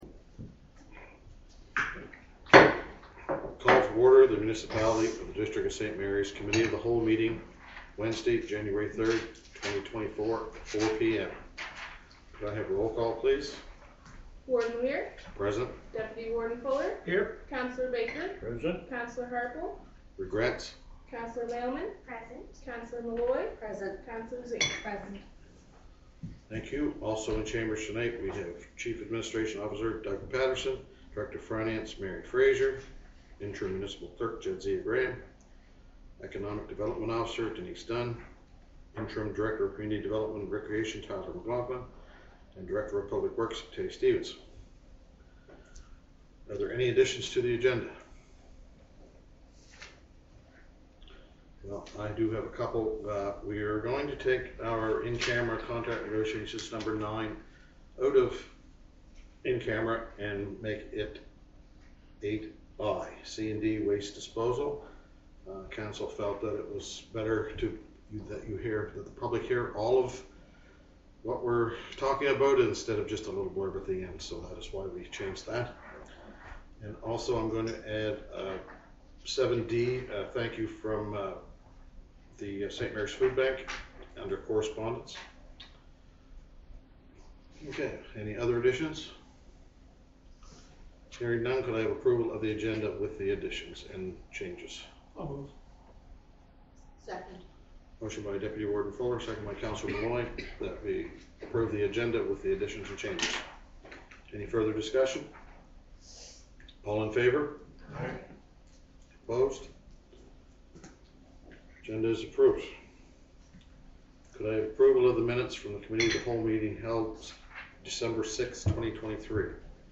April 14th, 2020- Regular Council Meeting.mp3